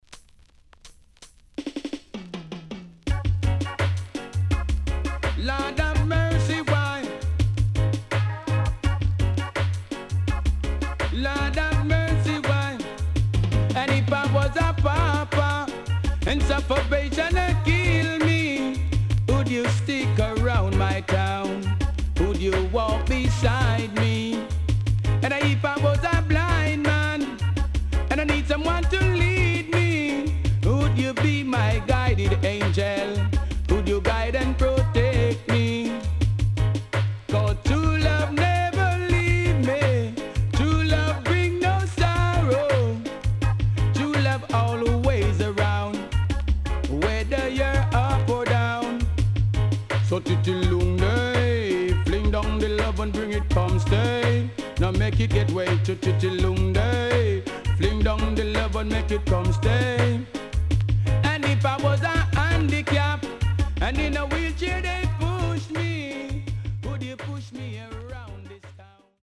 HOME > REISSUE USED [DANCEHALL]
SIDE A:うすいこまかい傷ありますがノイズあまり目立ちません。